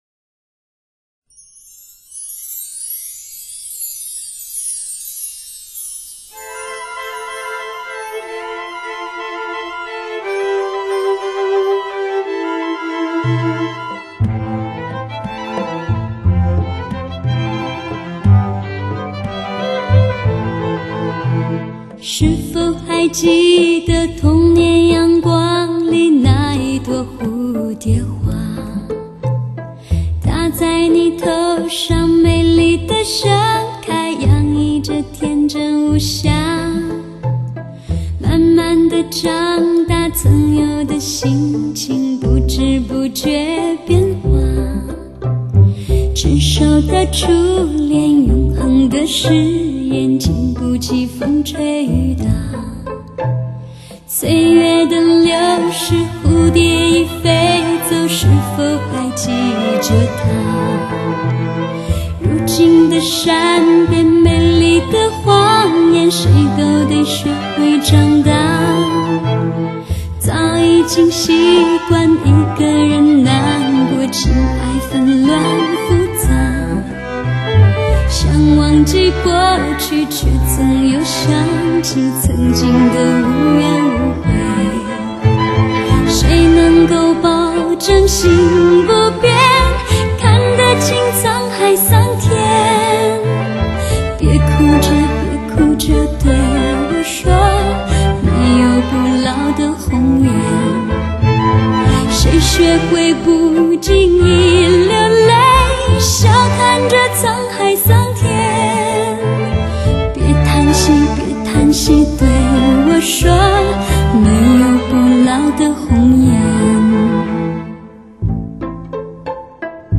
此外，在录制过程中动用12把大提琴、12把中提琴等75件弦乐器，气势磅礴的大型室内管弦乐伴奏更让此专辑增色不少。
整张专辑风格清新自然、优雅脱俗，十二首作品之间又各有各精彩。